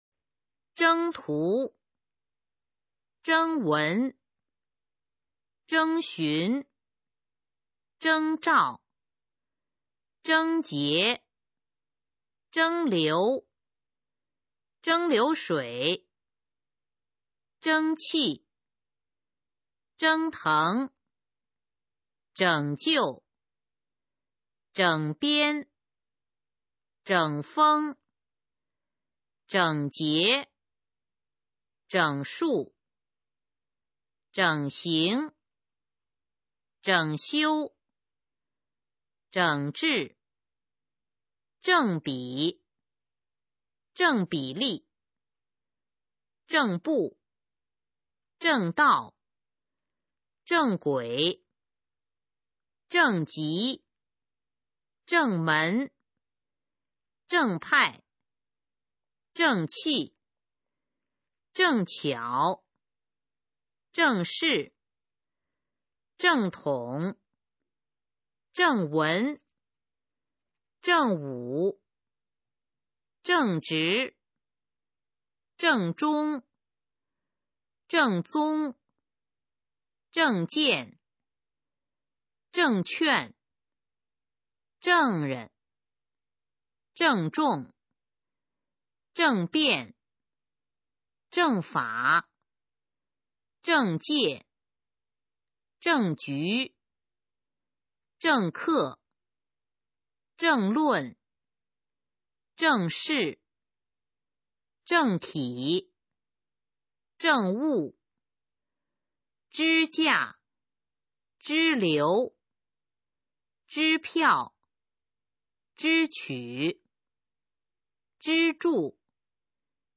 首页 视听 学说普通话 词语表（表二）